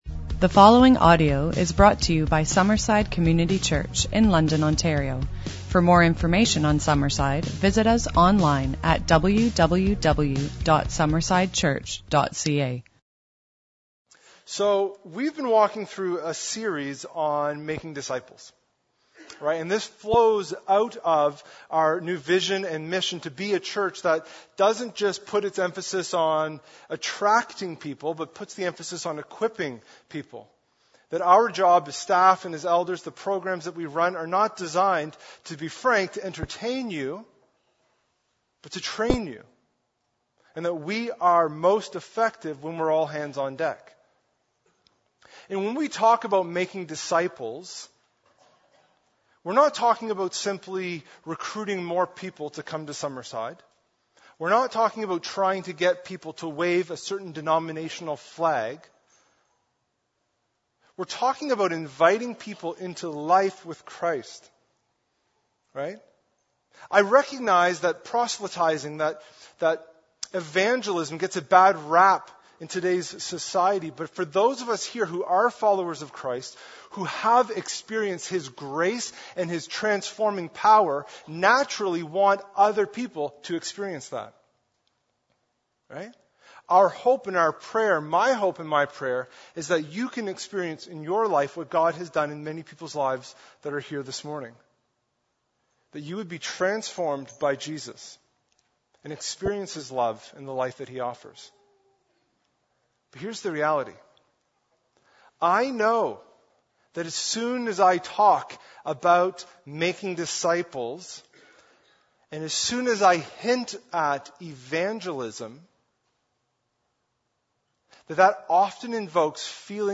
For many Christians, hearing a sermon on evangelism can often invoke feelings of guilt or shame.